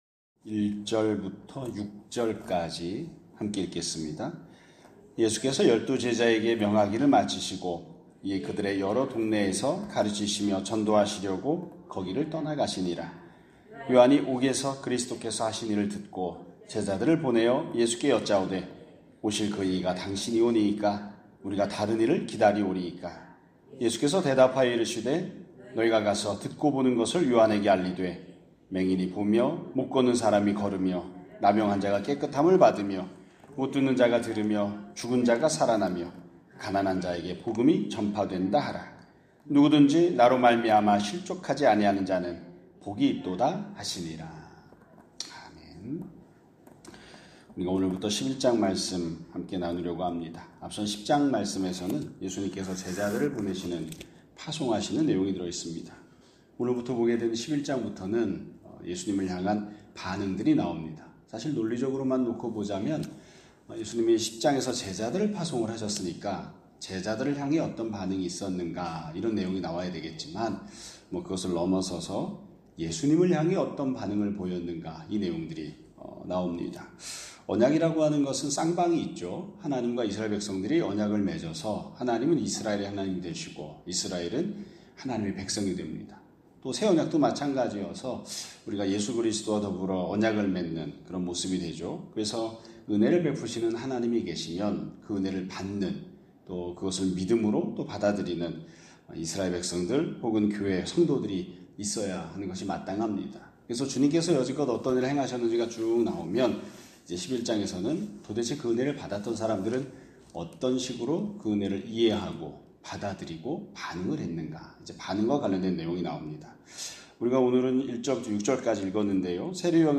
2025년 8월 25일 (월요일) <아침예배> 설교입니다.